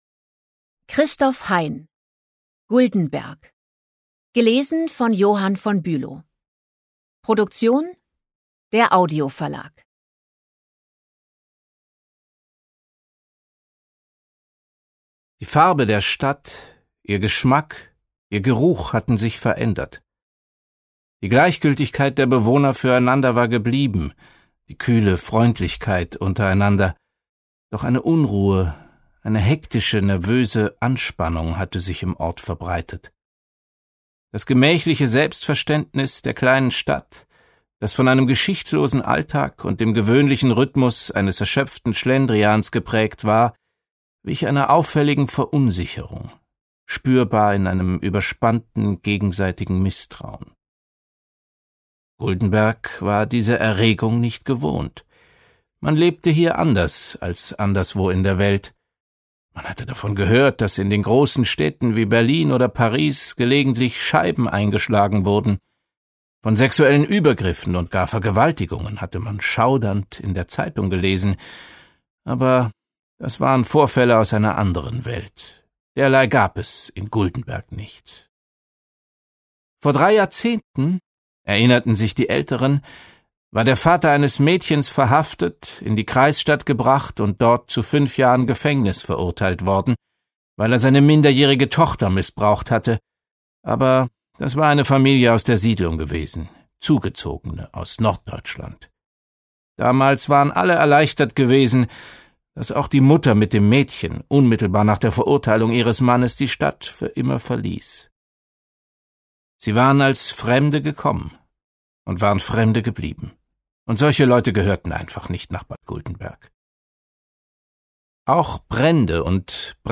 Ungekürzte Lesung